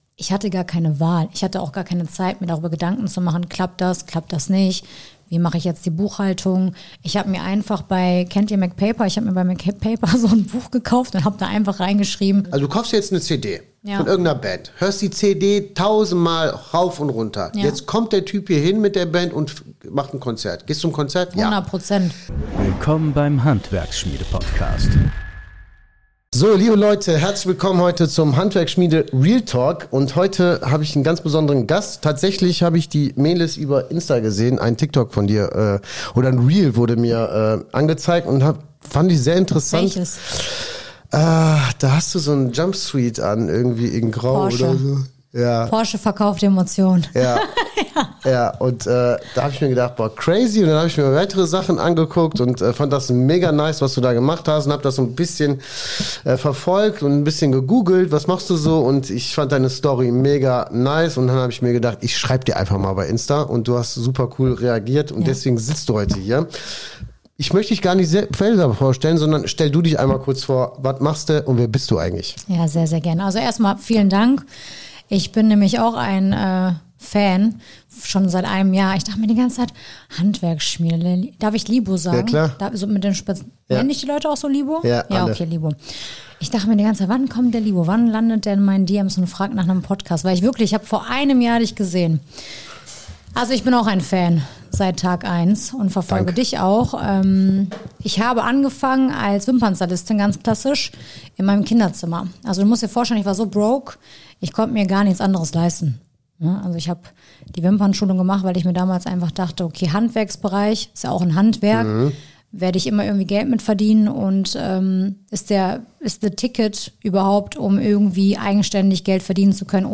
Vom Kinderzimmer zum Hochpreis-Beauty-Unternehmen | Interview